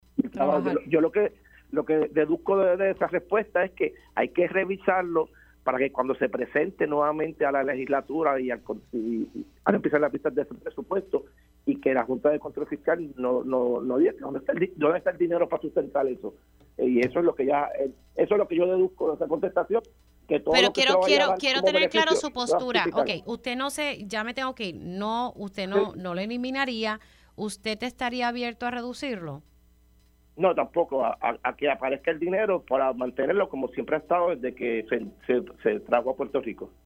En el caso del representante Jorge ‘Georgie’ Navarro Suárez, señaló que, en todo caso, el Crédito por Trabajo debe ser reforzado.